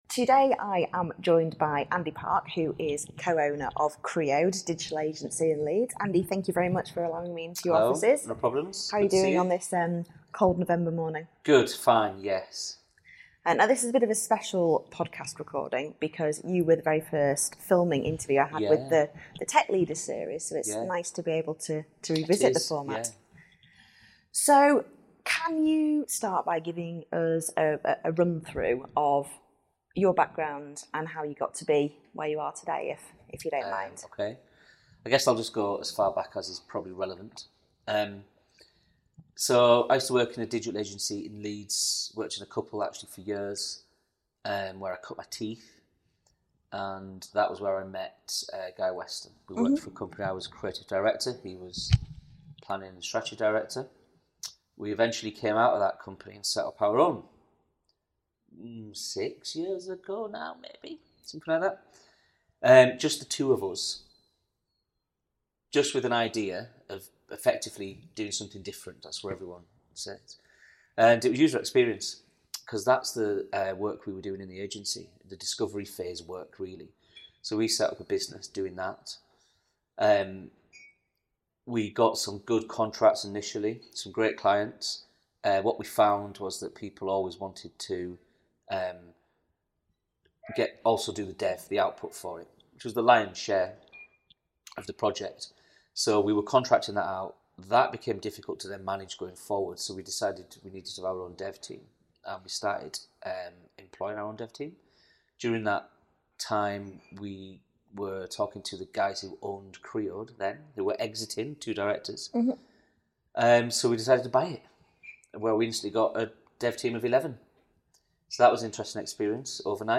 In this frank and off-the-cuff interview (34m)
Why obstacles are adventures And how black humour sees him through We recorded this interview in November 2019, so some of the conversation seems like a world away.